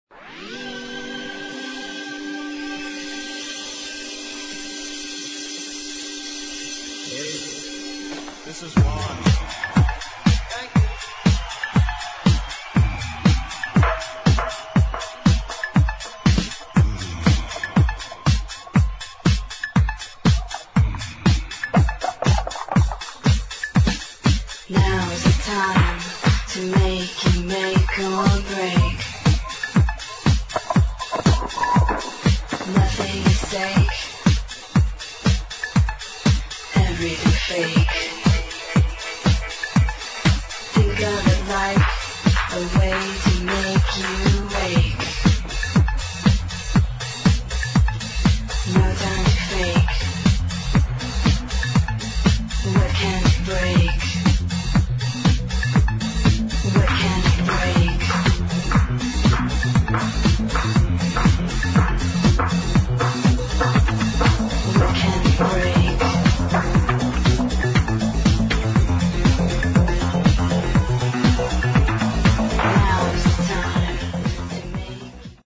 [ HOUSE | DISCO | ROCK ]
(Live)